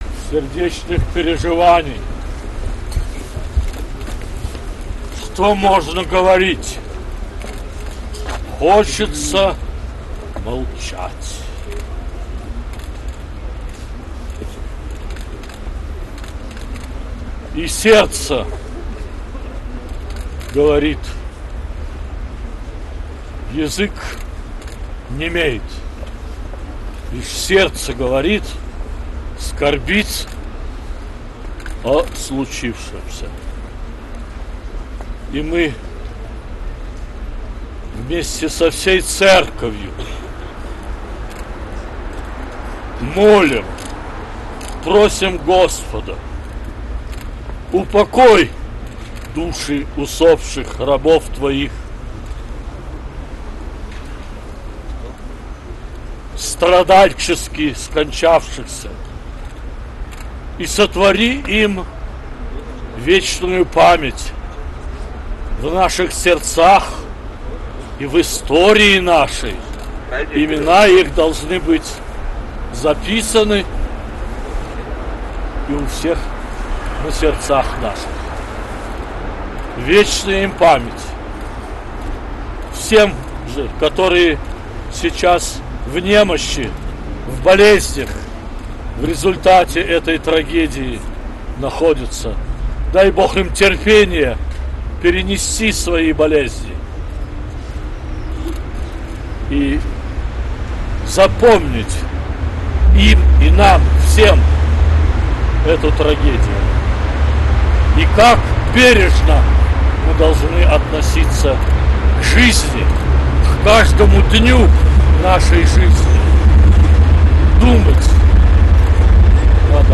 Аудиозапись слово Высокопреосвященнейшего Митрополита Филарета после посещения станции метро "Октябрьская"